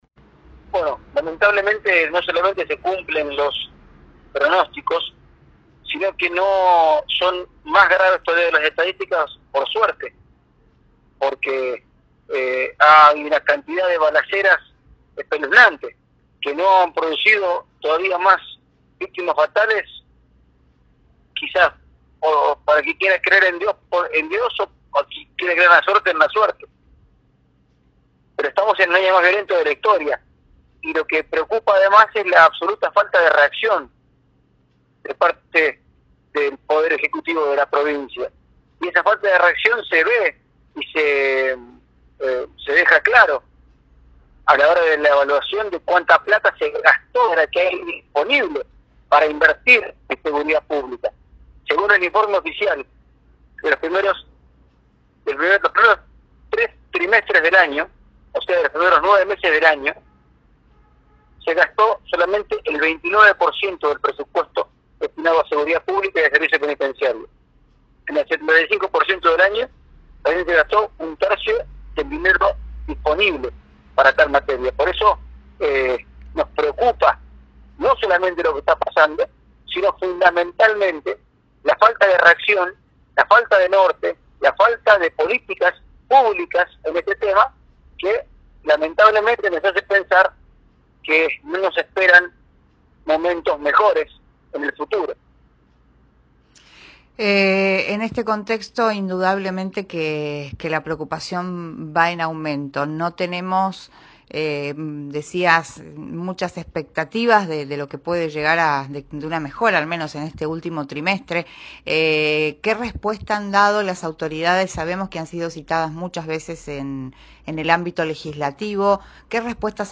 Así lo aseguró a Cadena 3 Rosario el diputado provincial Juan Cruz Cándido de la UCR Evolución.